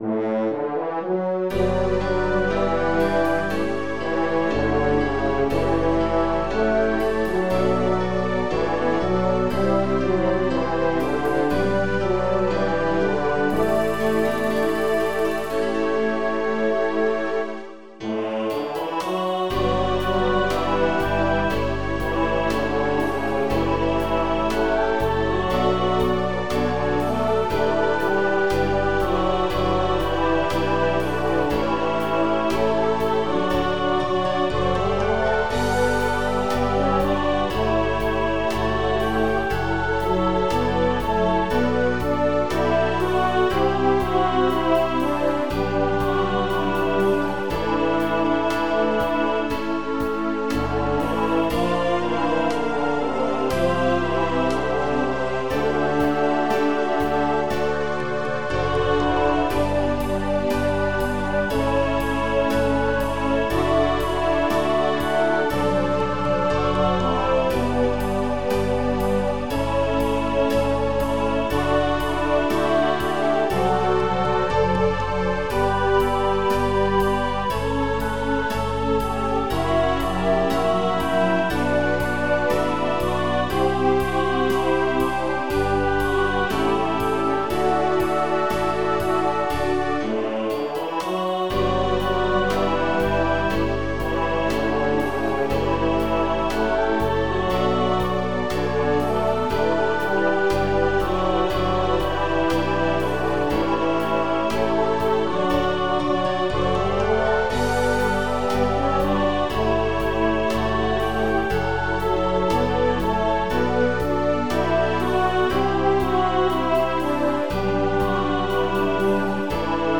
Mai 2023) Herbstlager JW/BR Leuggern in Lumnezia (GR): Lagersong "Das Leben ist magisch" (mp3)
Lagersong 2023 Das Leben ist magisch.mp3